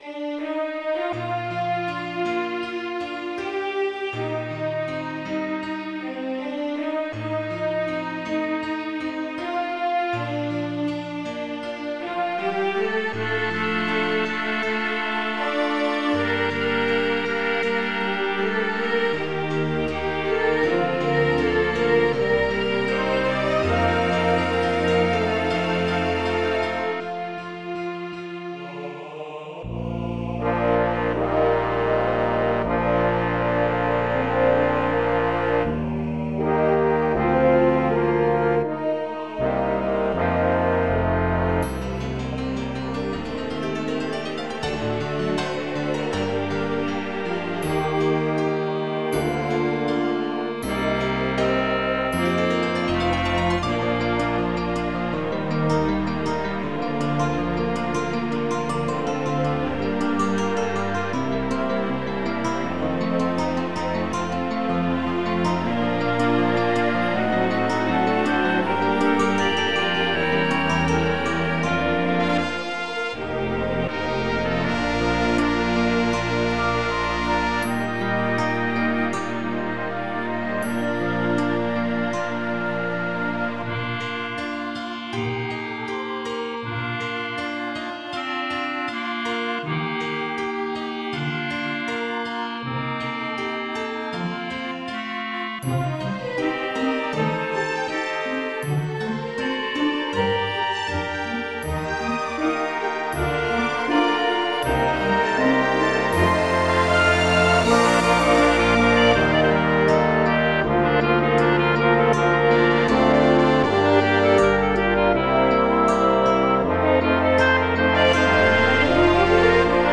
Root > _Разное > Kompozitory_new > Комиссаров В > Симфонический > Творец миров